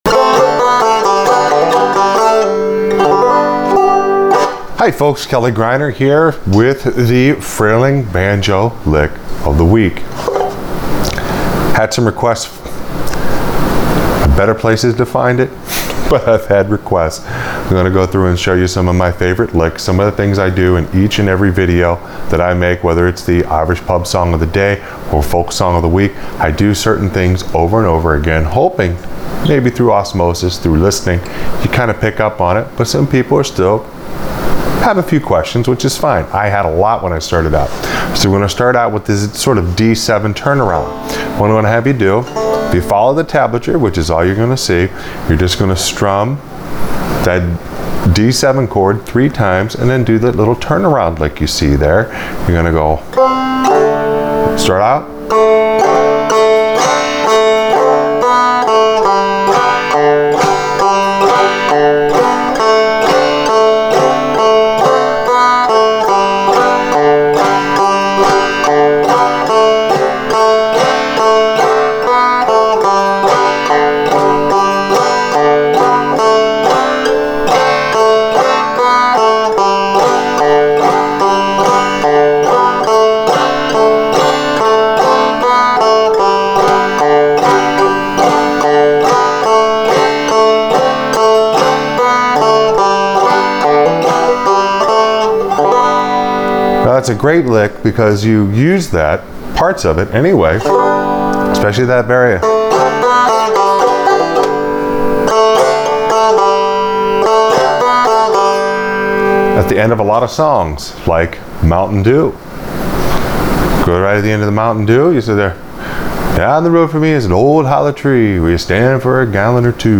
Frailing Banjo Lick Of The Week – The D7 Turnaround
This week it is the very, very common D7 turn around lick.